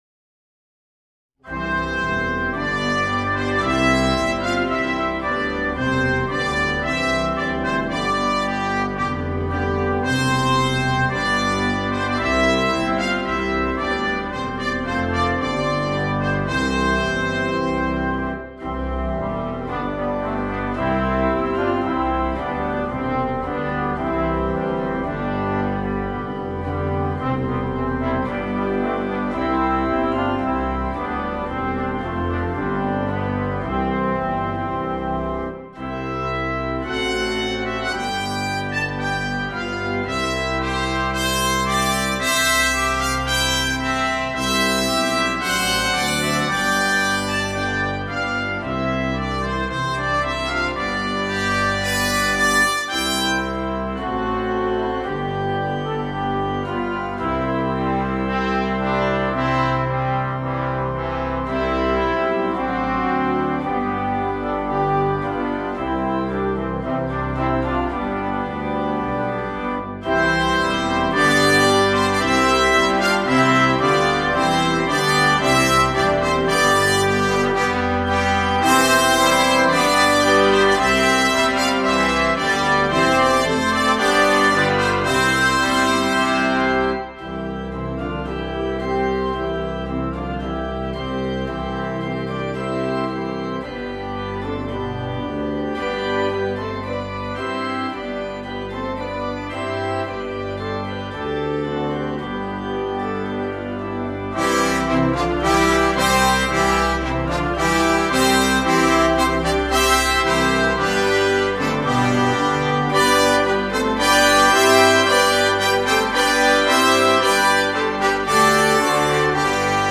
with organ
Classical
Part 1: Bb Trumpet, Bb Cornet
Part 3: F Horn
Part 4: Trombone, Euphonium – Bass clef
Organ accompaniment